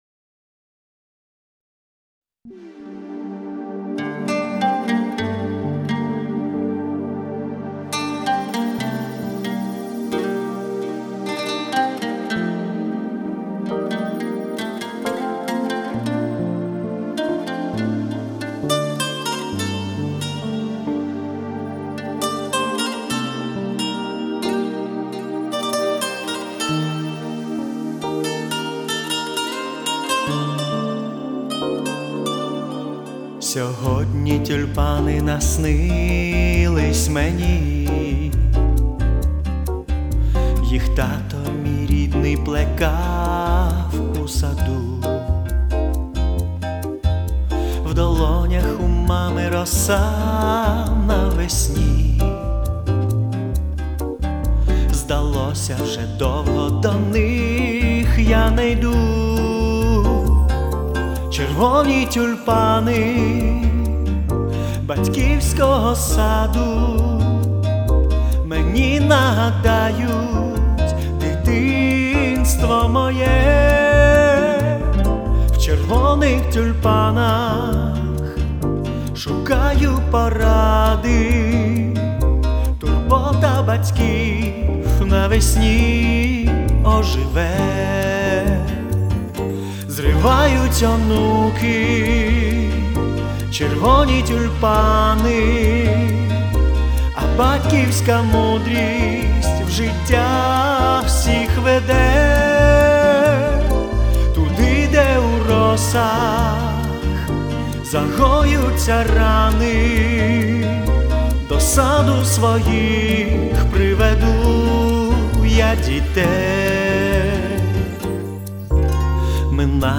Красива пісня.
Мелодійно... 08